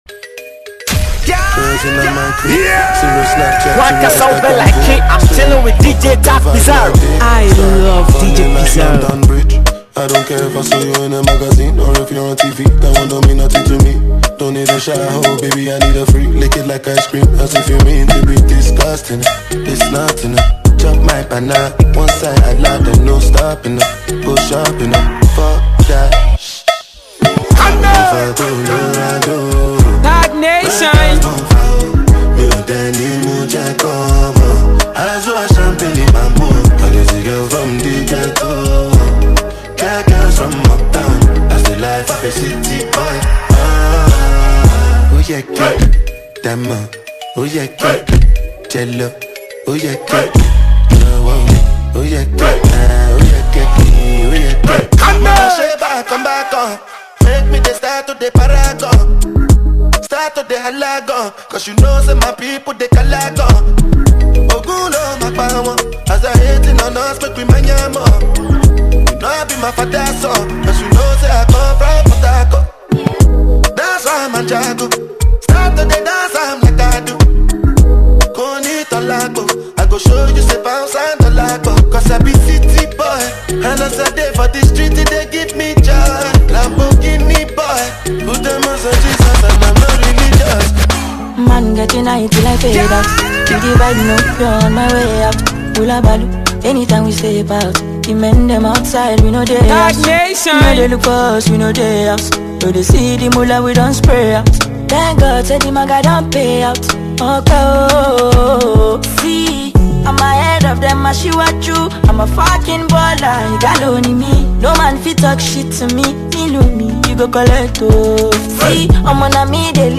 classical contemporary music mixtape
Genre: Mixtape